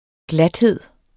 Udtale [ ˈgladˌheðˀ ]